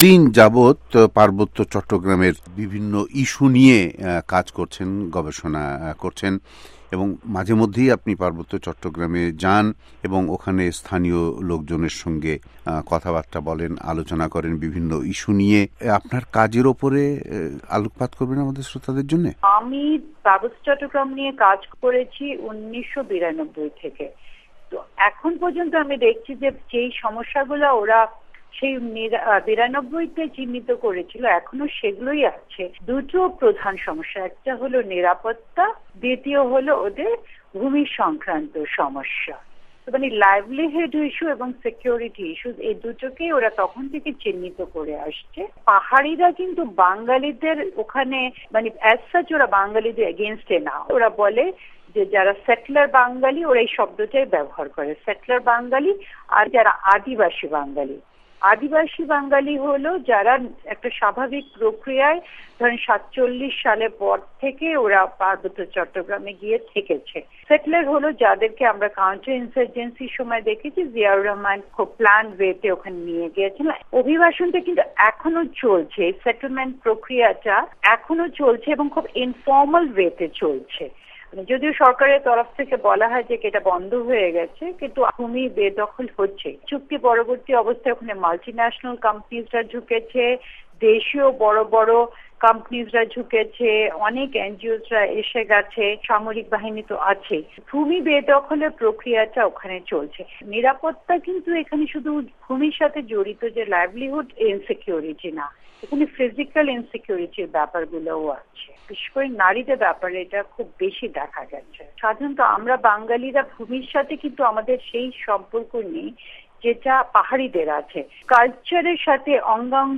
আমরা তাঁর কাজ নিয়ে তাঁর সঙ্গে কথা বলি । ওয়াশিংটন স্টুডিও থেকে তাঁর সঙ্গে কথা বলেন